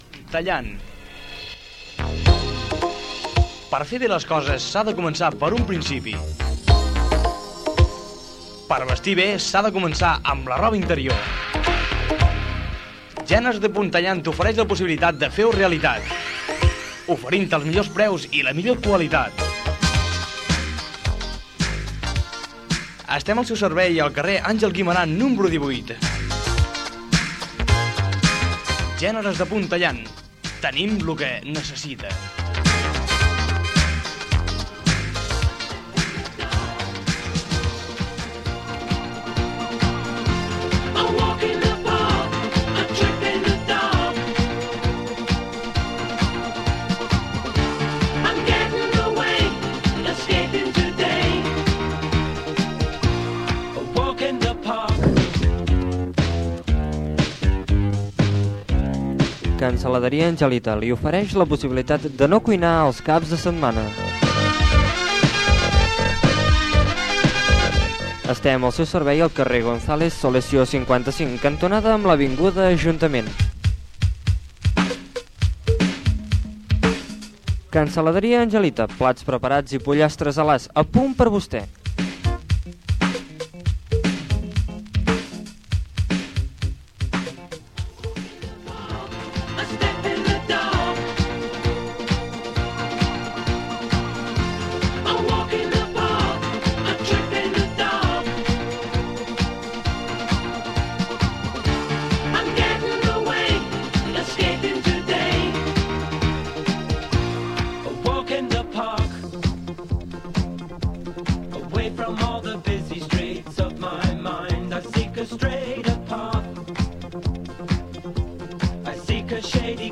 f904defdd68815f6223aeda13e2b86443fbfb729.mp3 Títol Ràdio Súria Emissora Ràdio Súria Titularitat Pública municipal Descripció Publictat, promoció festa major i indicatiu.